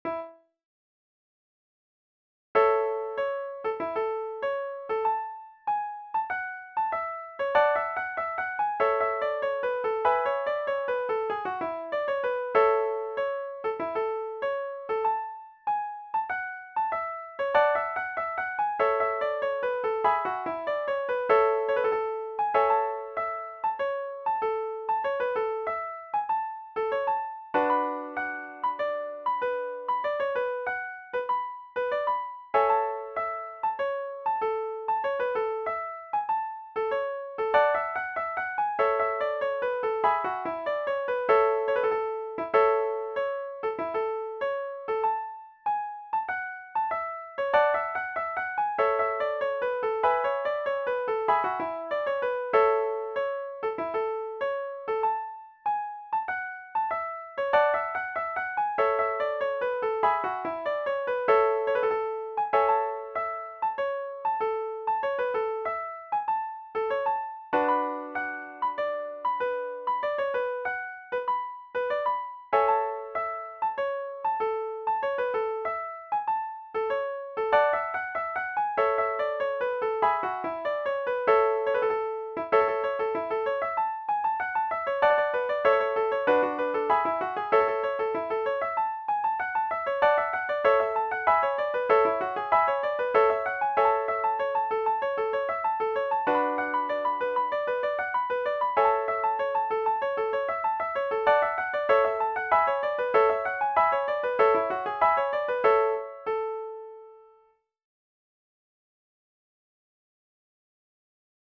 DUET or ENSEMBLE Violin Solo
Celtic/Irish